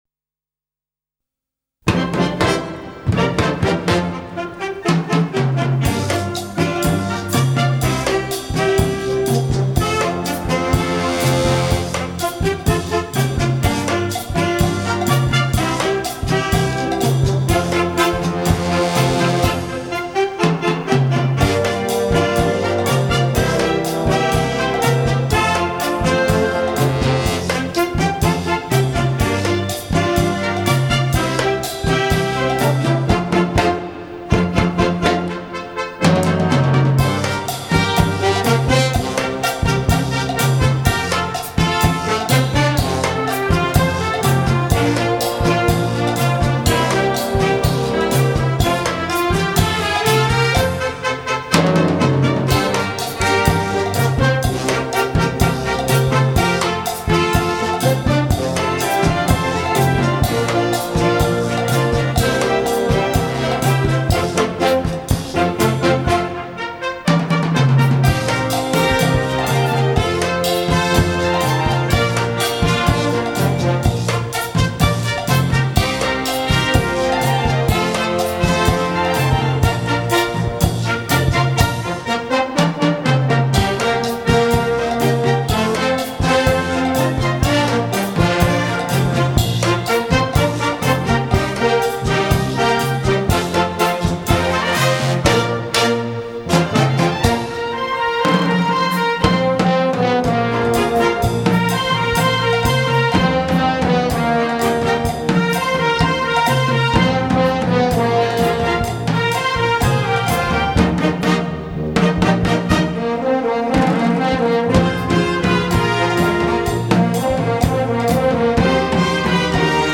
Gattung: Cha-Cha-Cha
Besetzung: Blasorchester